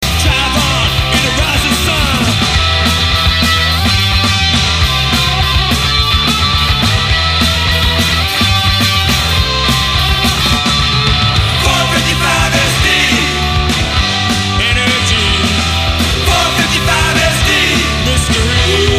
It still has that shrill guitar and tin drum sound.